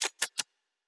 Weapon 04 Reload 3.wav